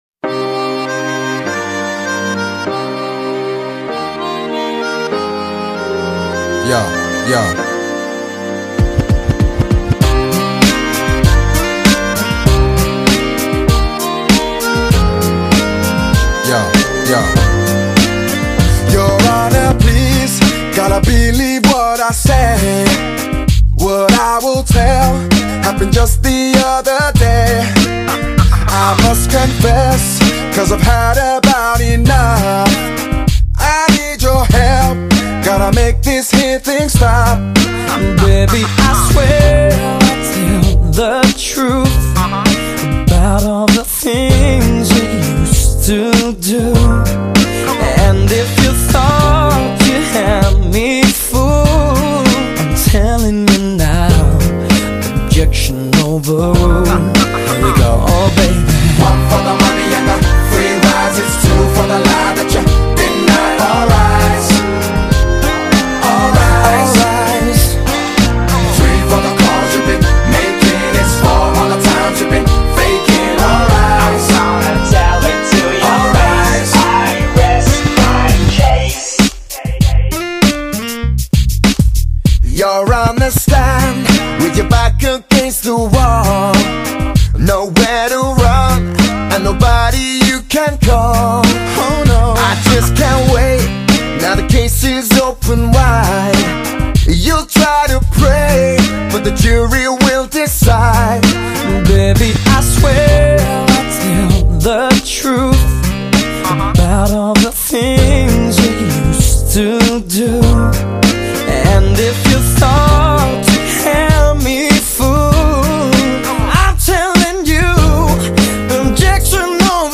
类型: 电音炫音
动感兼具新潮的蓝调热歌，谢谢一板  ！